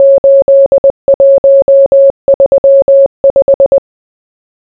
morse.wav